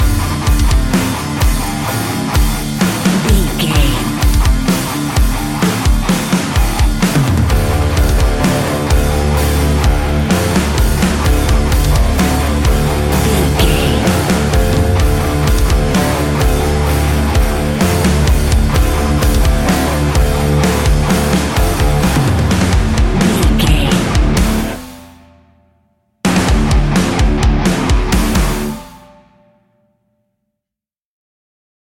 Ionian/Major
hard rock
guitars
heavy metal